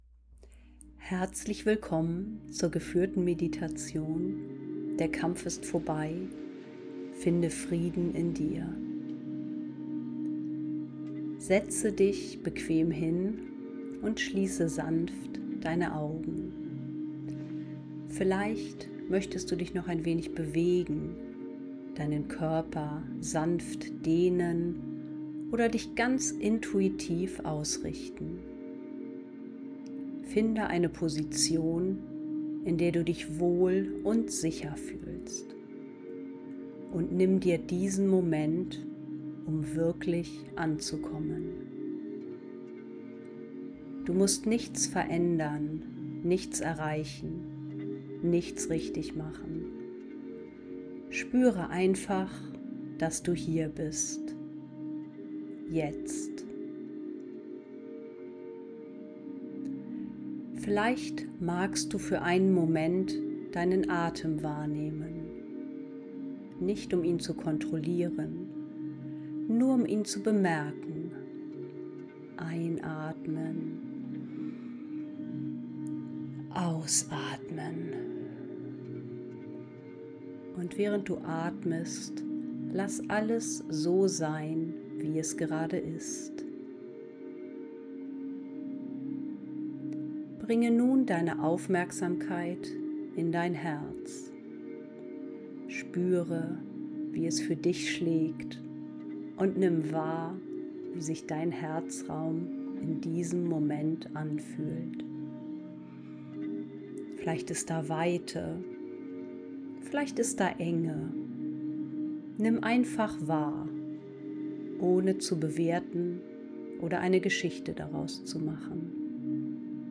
Diese geführte Meditation lädt dich ein, den inneren Kampf zu beenden und allen Druck loszulassen. Sanft führt sie dich in eine tiefe Weite und erinnert dich daran: Du bist bereits geliebt.